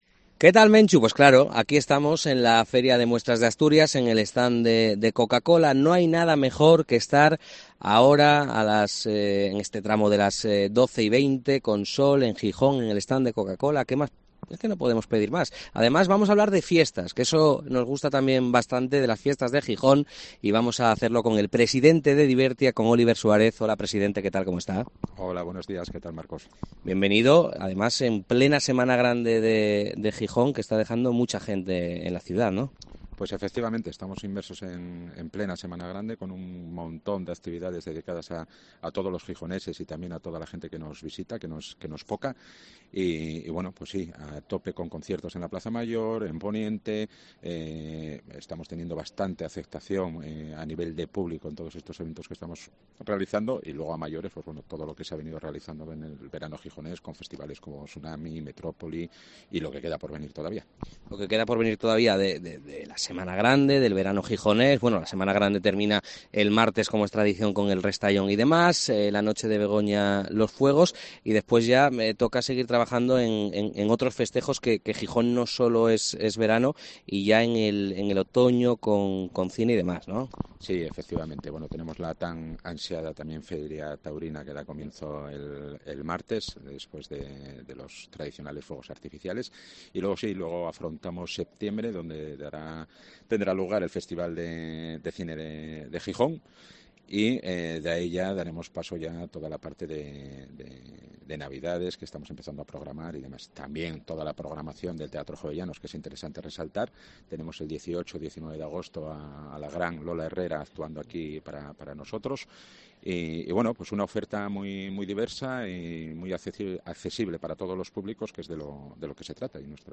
El concejal gijonés y presidente de la empresa pública que gestiona los festejos de la ciudad ha estado en el especial de COPE desde el Recinto Ferial Luis Adaro
FIDMA 2023: entrevista a Óliver Suárez, presidente de Divertia Gijón